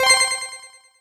snd_shineselect.wav